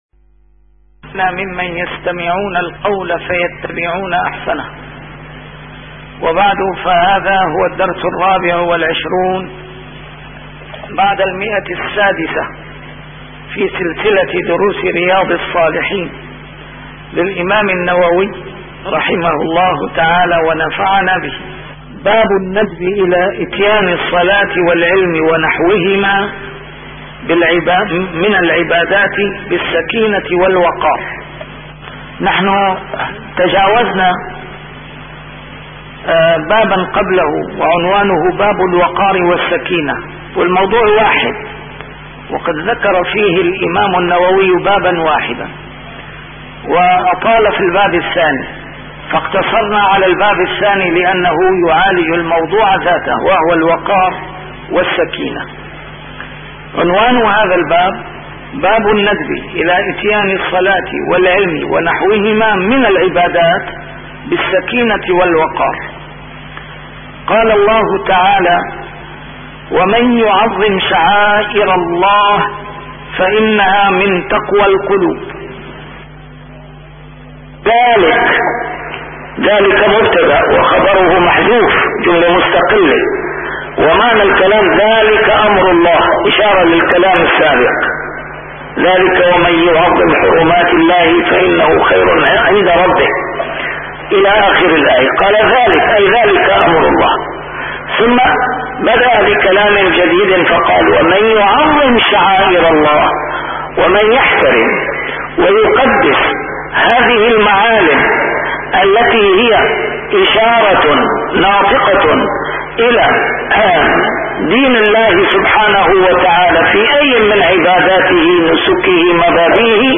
A MARTYR SCHOLAR: IMAM MUHAMMAD SAEED RAMADAN AL-BOUTI - الدروس العلمية - شرح كتاب رياض الصالحين - 624- شرح رياض الصالحين: إتيان العبادات بالسكينة والوقار